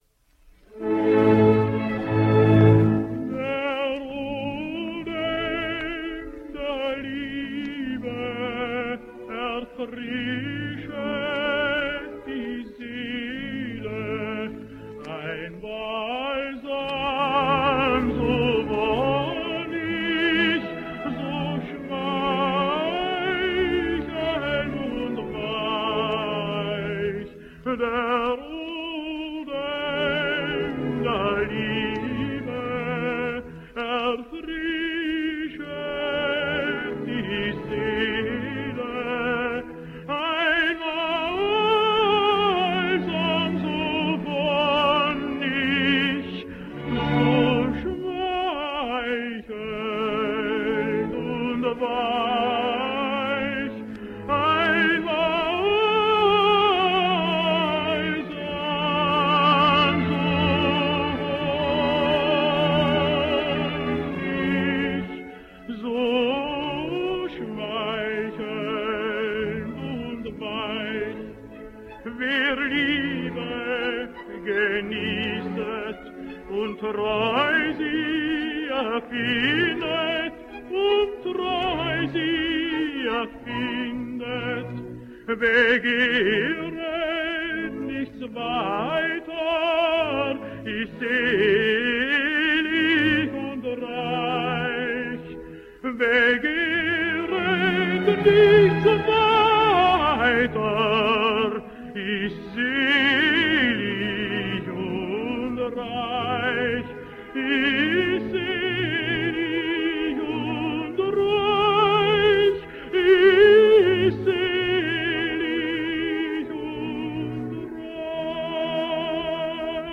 Austrian tenor.
Not only were the words, crystal clear but the tone, fitted the drama.
Here he is, in one of his Mozart’s’ roles, Fernando’s Aria from Cosi Fan Tutte.